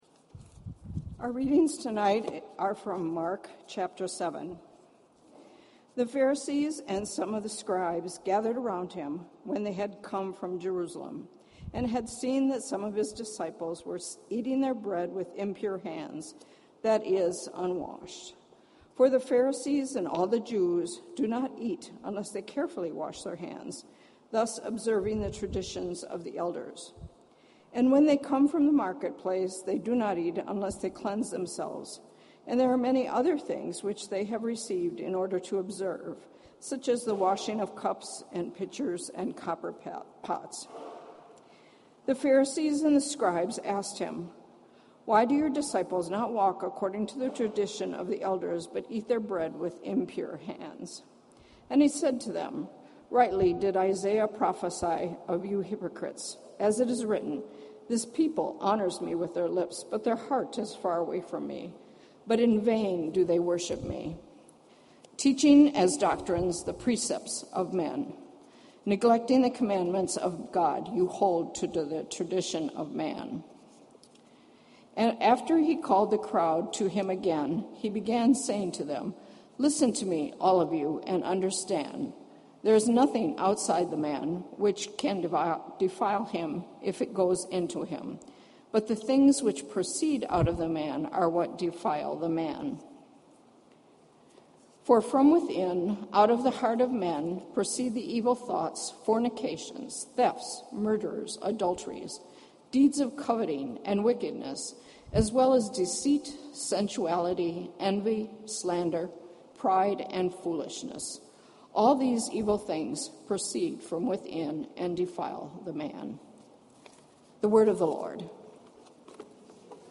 Scripture Reading: Mark 7 This entry was posted in Sermons .